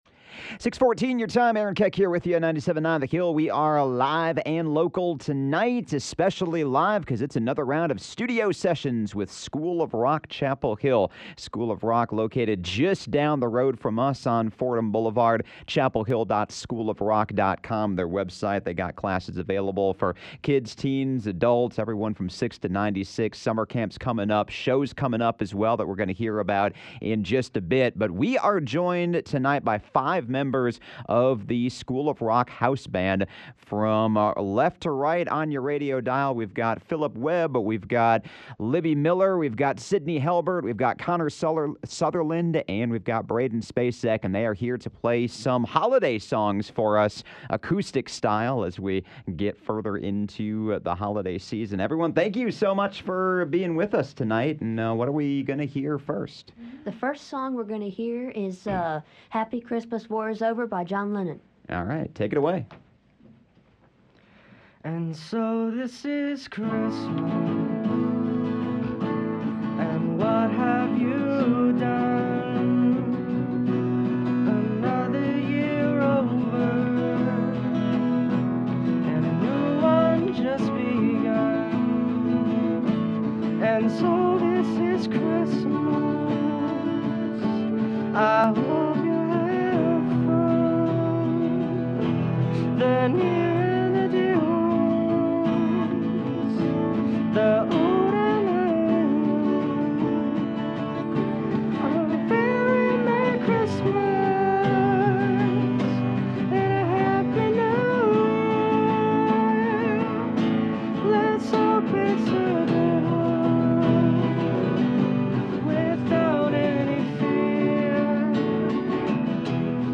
It’s time for another round of Studio Sessions with the School of Rock Chapel Hill, featuring three holiday classics performed by the House Band!